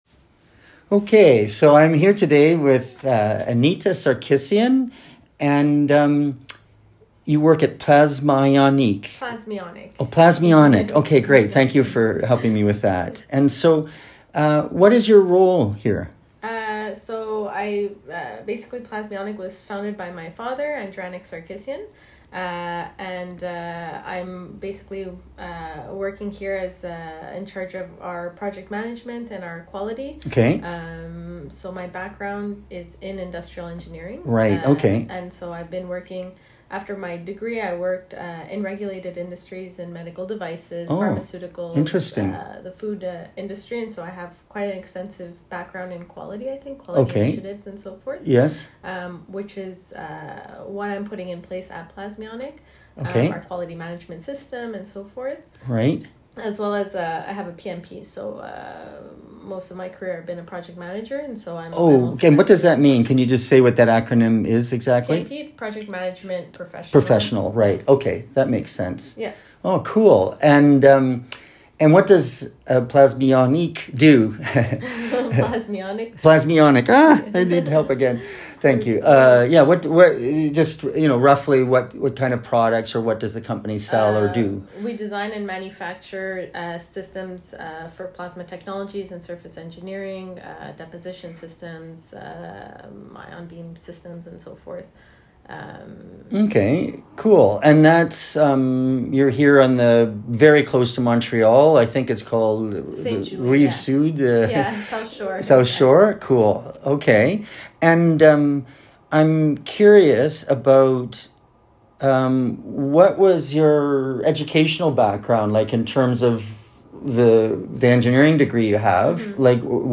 An Audio Interview